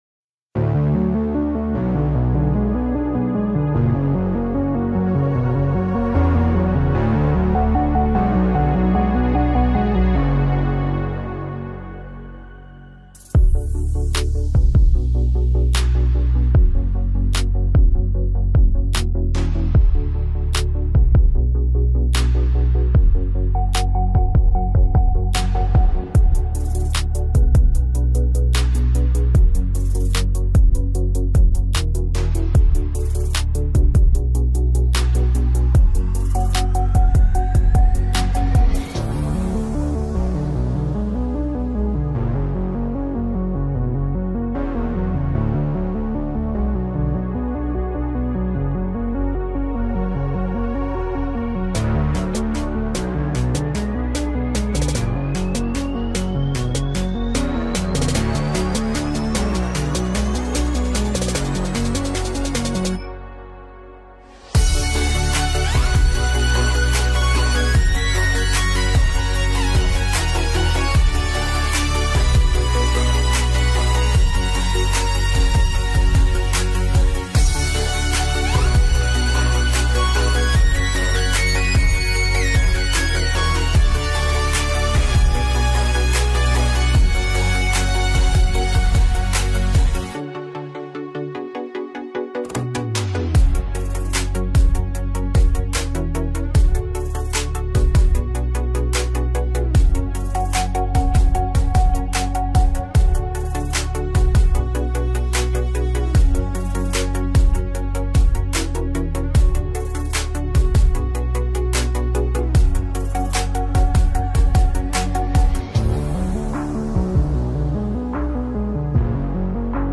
Instrumentais